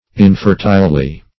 infertilely - definition of infertilely - synonyms, pronunciation, spelling from Free Dictionary Search Result for " infertilely" : The Collaborative International Dictionary of English v.0.48: Infertilely \In*fer"tile*ly\, adv. In an infertile manner.